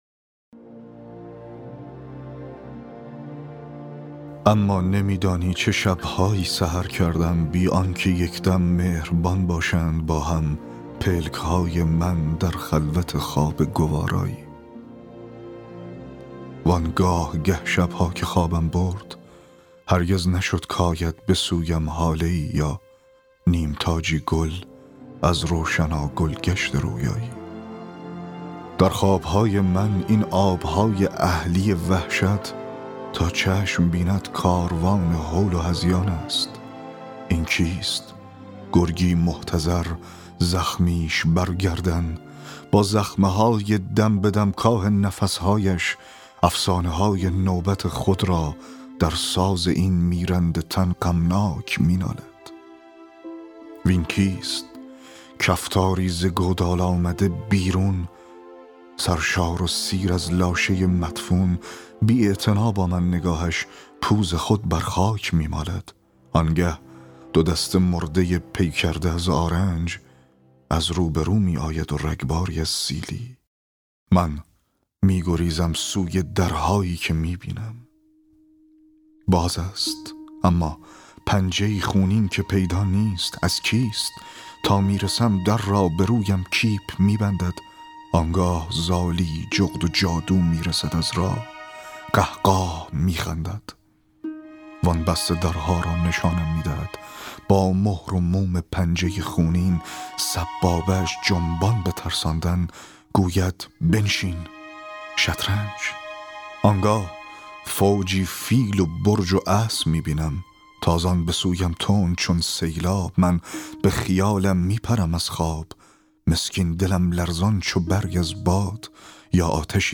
فایل صوتی دکلمه شعر آنگاه پس از تندر